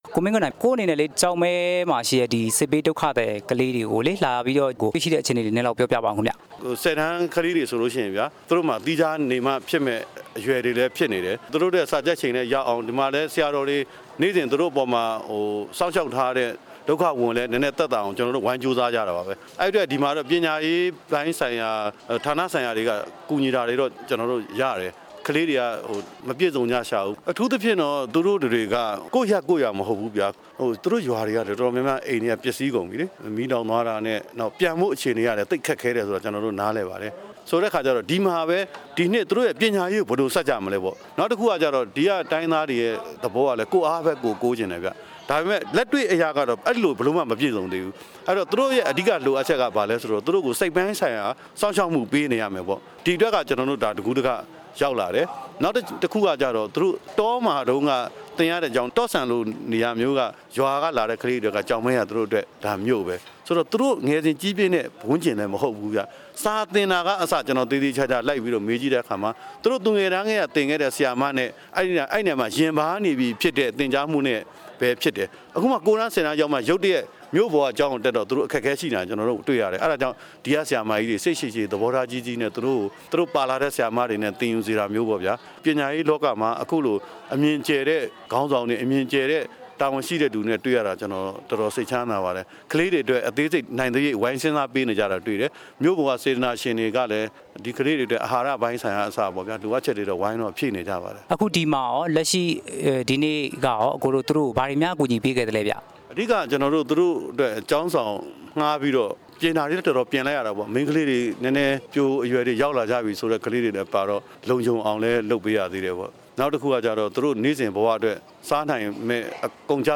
သြဂုတ်လ ၃ ရက်နေ့ မနေ့က ရှမ်းပြည်မြောက်ပိုင်း ကျောက်မဲမြို့မှာ စစ်ဘေးဒုက္ခသည် တက္ကသိုလ်ဝင် တန်းဖြေ ဆိုကြမယ့် ကျောင်းသူ၊ ကျောင်းသားတွေနေထိုင်ဖို့ မေတ္တာအိုးဝေ အဆောင်ဖွင့်လှစ်ပွဲမှာ ကိုမင်းကိုနိုင် က ပြောကြားခဲ့တာ ဖြစ်ပါတယ်။